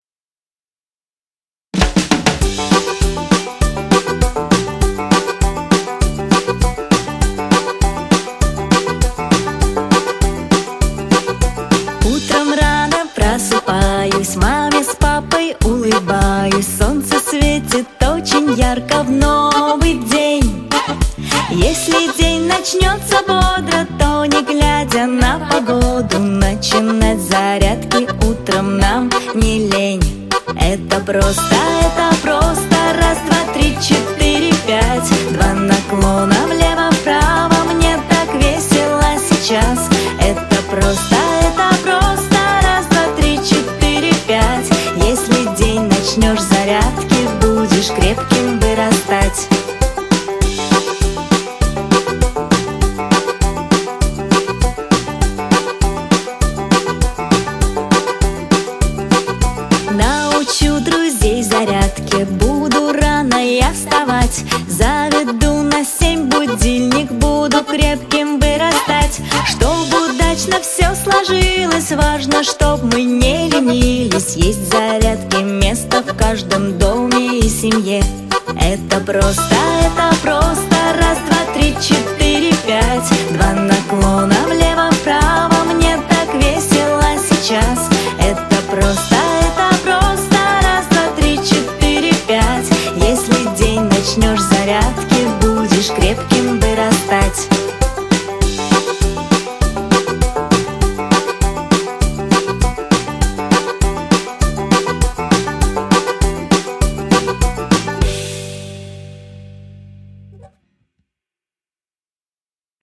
Детские песни